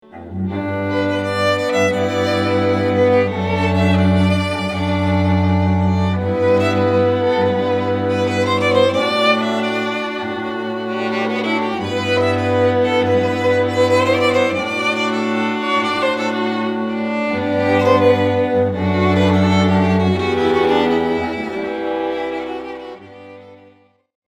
Bollywood Acoustic String Quartet
A list of some of their Bollywood arrangements